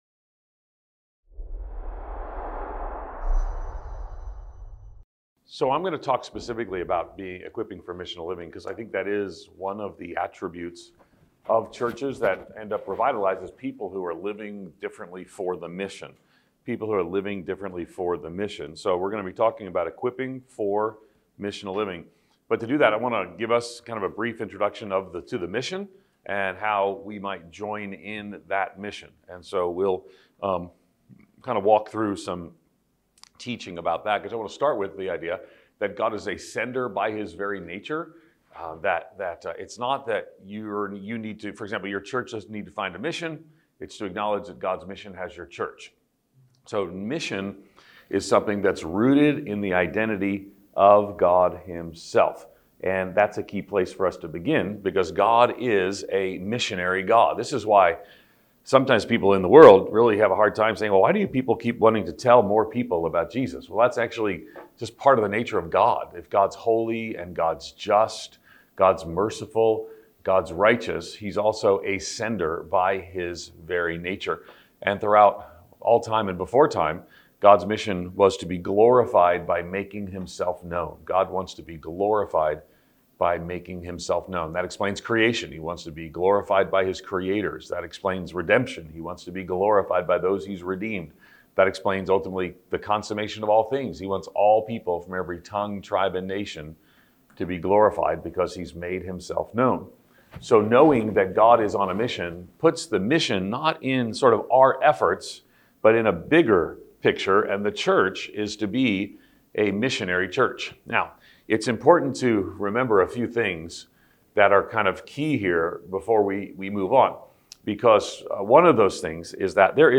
Speaker: Ed Stetzer | Event: ELF Church Revitalisation Network